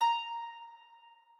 harp1_6.ogg